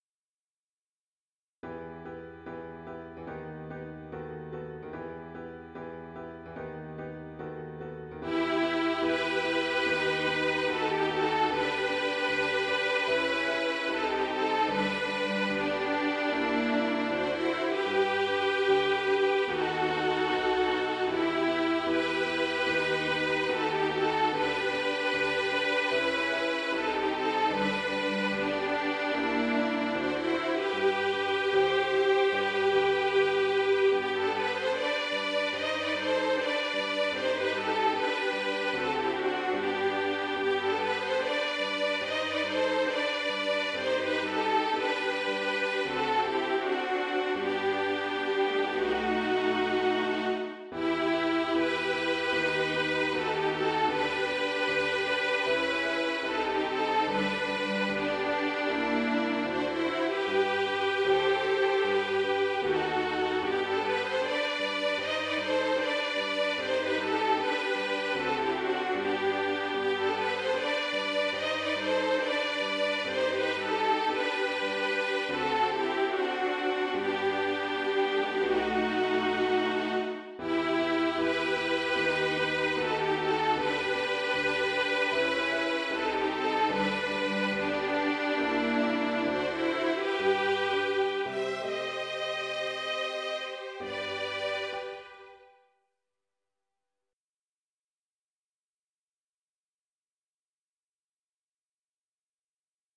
• Soprano I ONLY